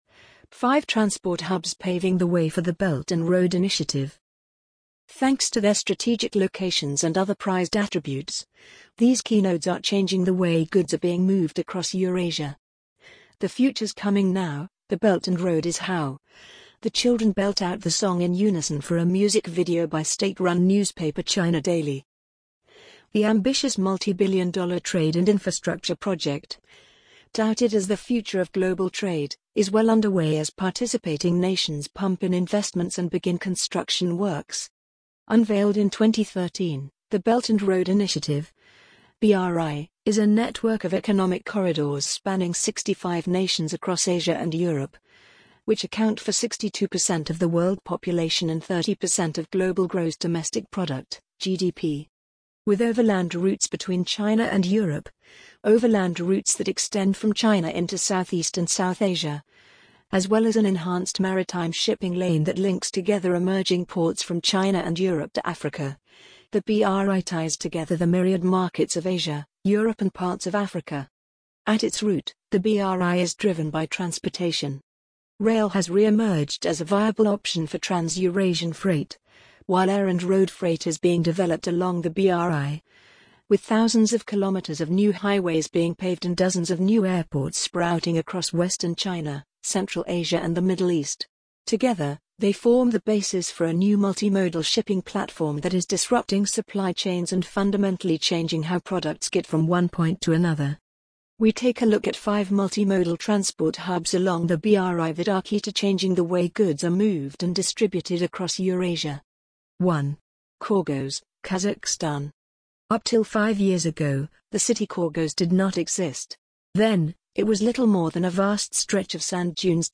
amazon_polly_3124.mp3